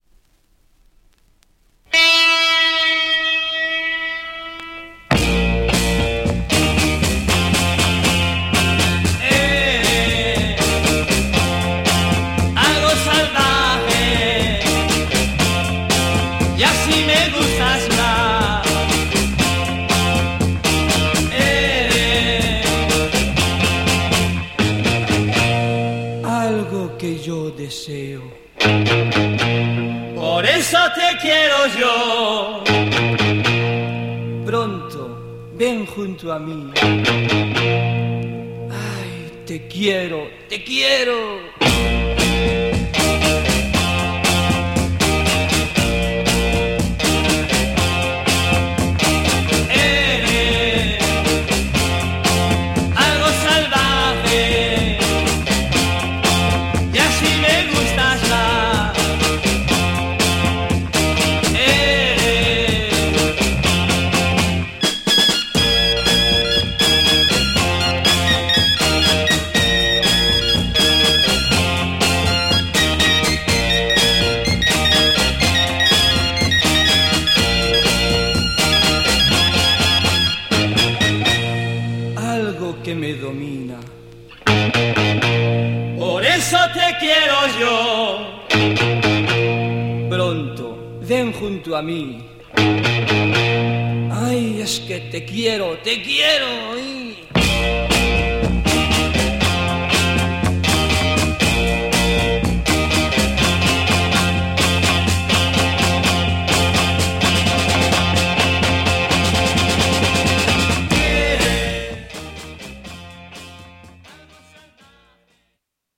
Rare Spanish Garage EP 66!